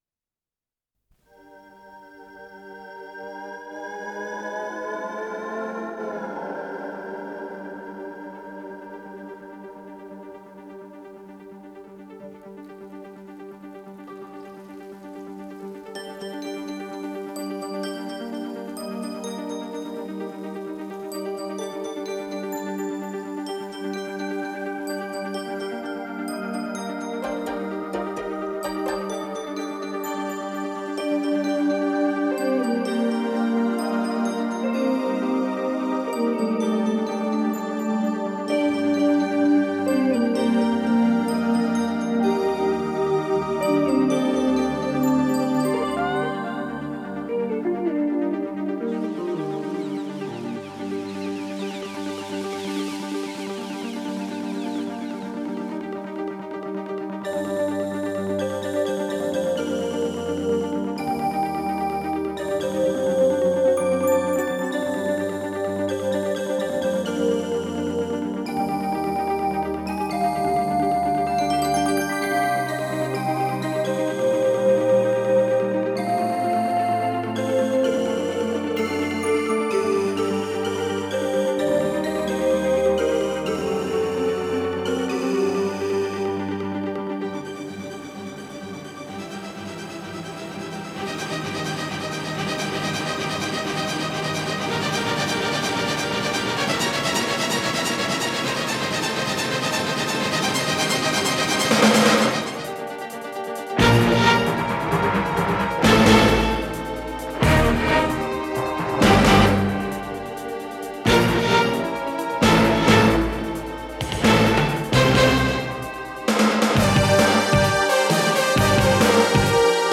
с профессиональной магнитной ленты
ПодзаголовокПьеса
Скорость ленты38 см/с
Тип лентыORWO Typ 106